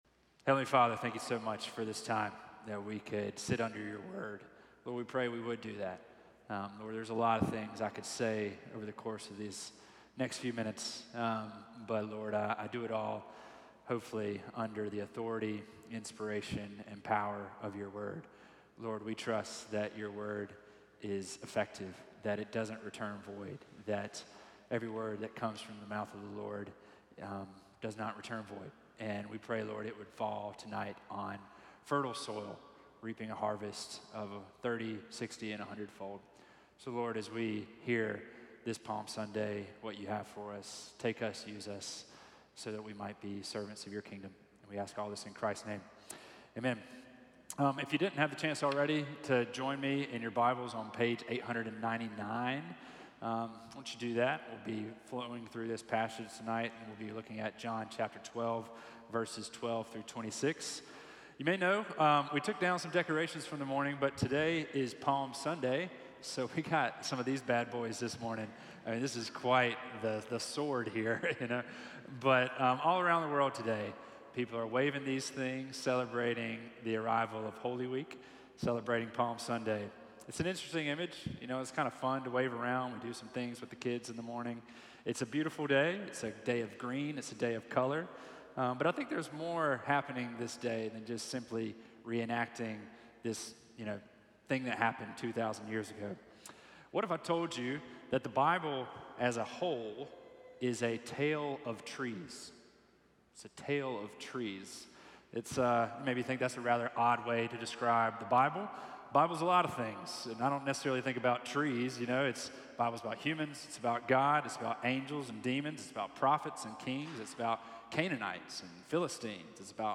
Please enjoy our Sunday sermons presented each week from Holy Trinity Anglican Church.